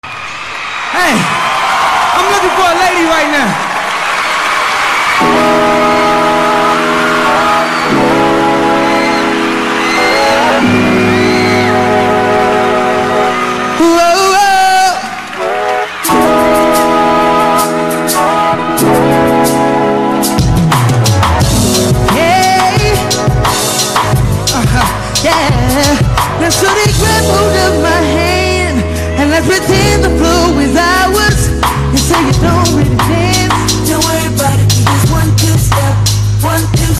BET Awards 2006